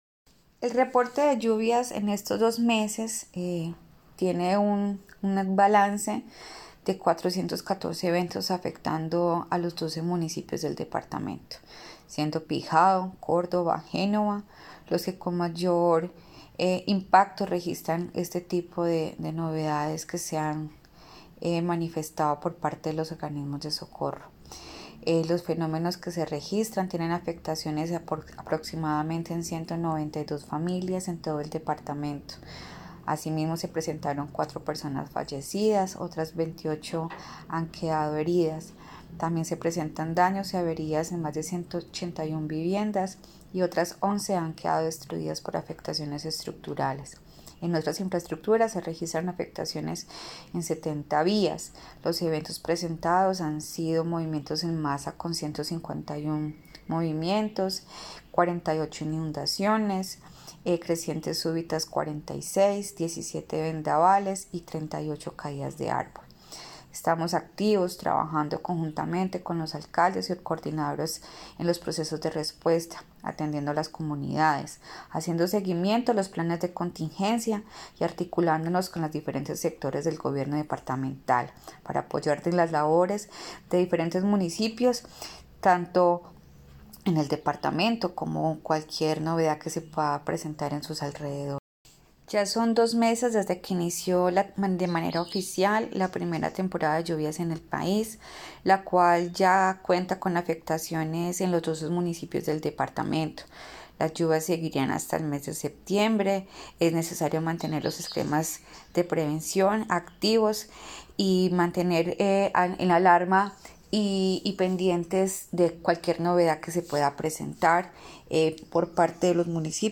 Audio de Mónica María Camacho Valladares, directora Udegerd Quindío, reporte de lluvias: